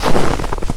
STEPS Snow, Walk 14.wav